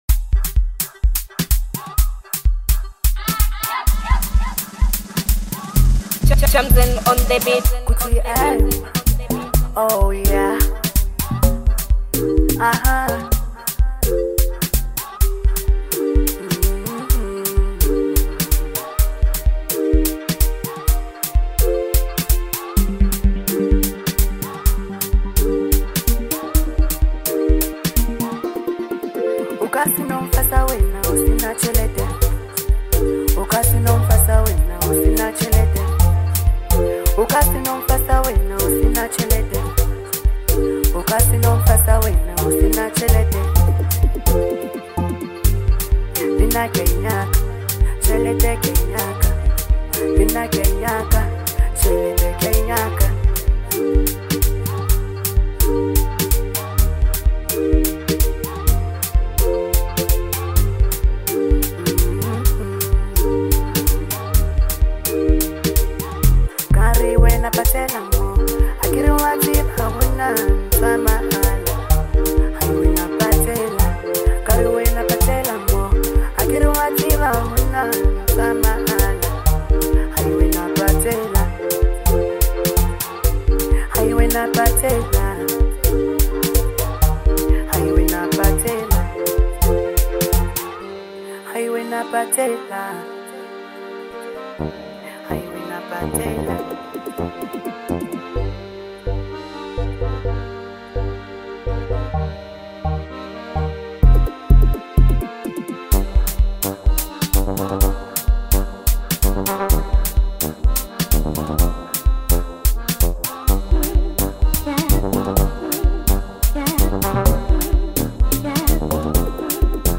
South African singer-songwriter